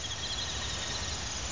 1 channel
Birdies .mp3